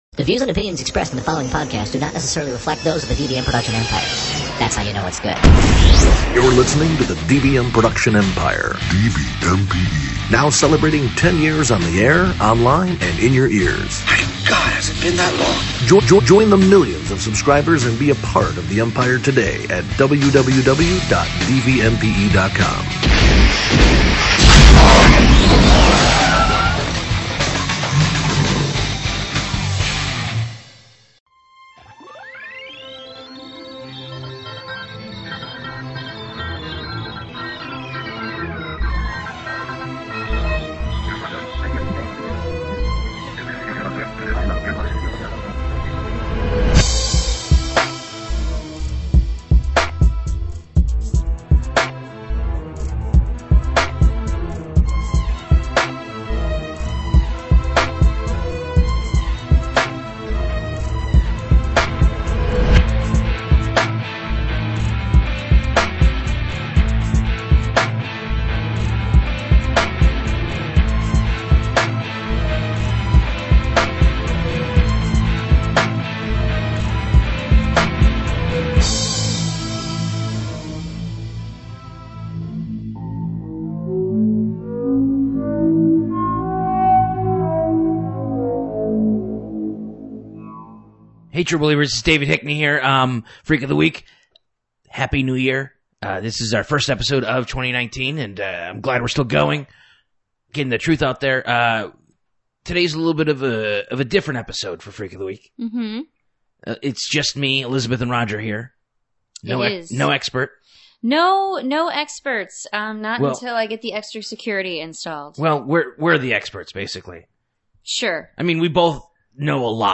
They are in the studio by themselves and are taking calls from any true believer about any creature or phenomena!